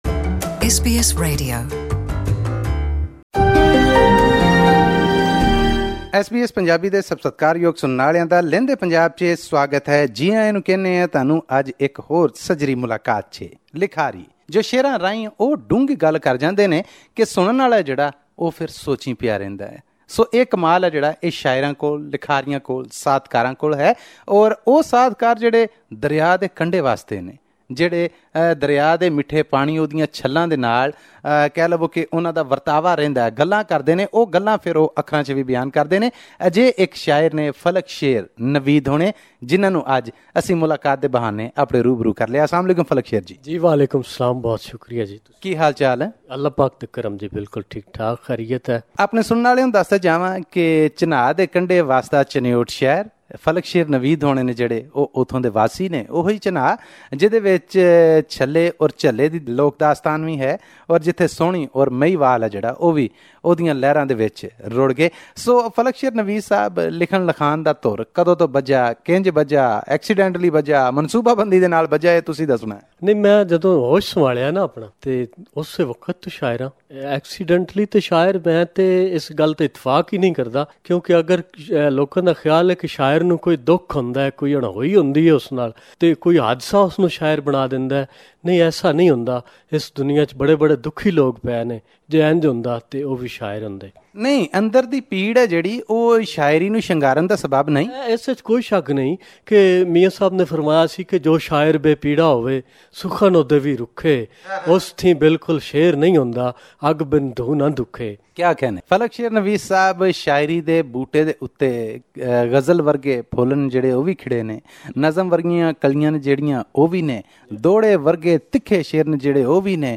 Every fortnight, we interview a well-known Punjabi from Pakistan. This time around, it's a poet.